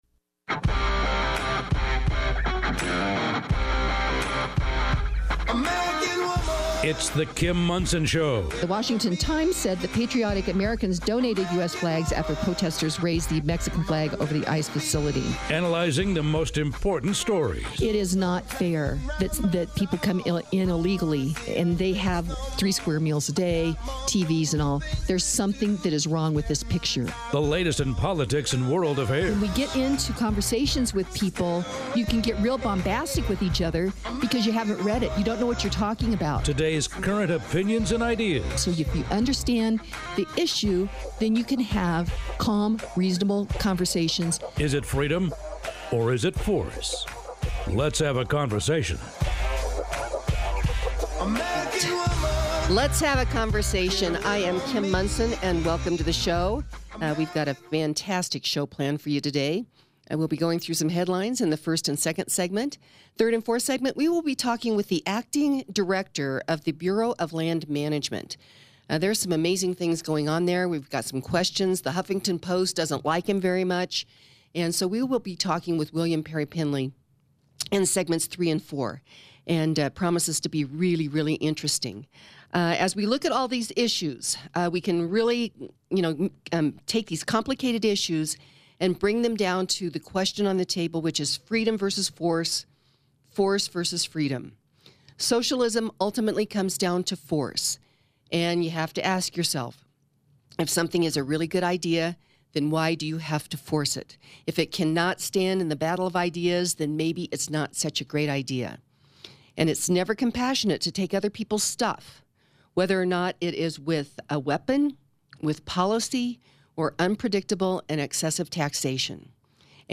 A Conversation with William Perry Pendley on the Future of the Bureau of Land Management